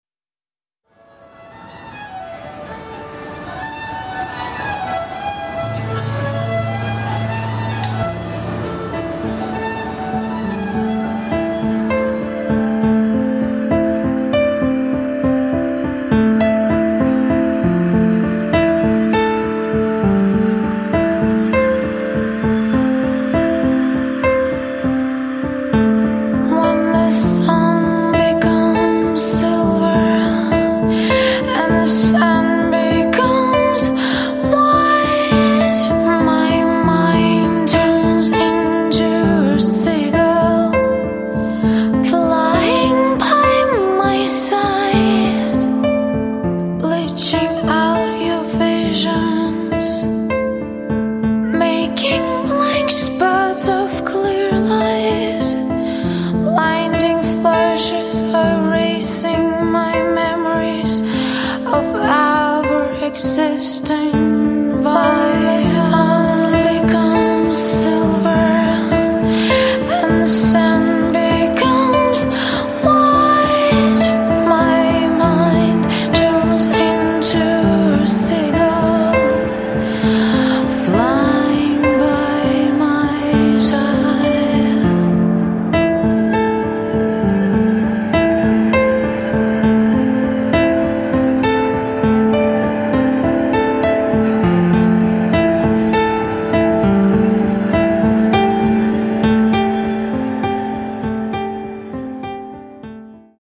songs :/